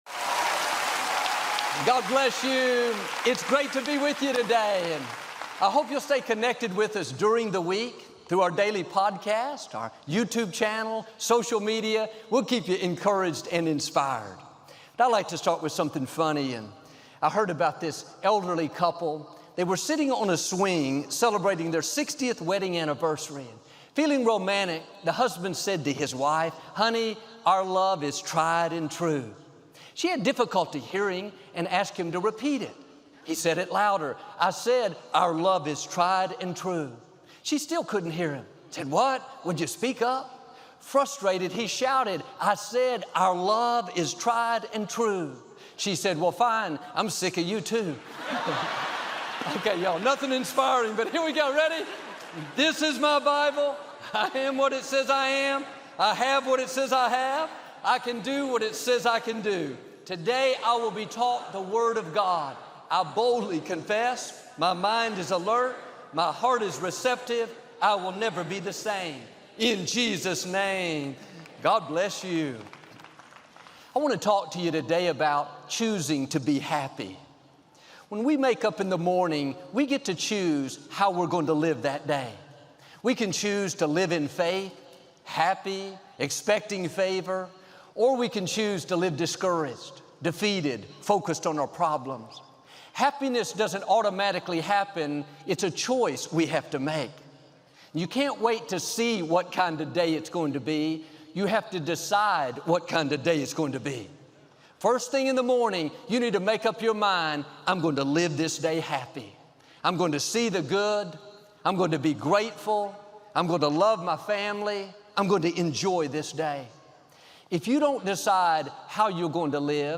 Pastor Osteen’s engaging speaking style, peppered with relatable anecdotes and motivational stories, creates a compelling narrative that resonates with a broad audience.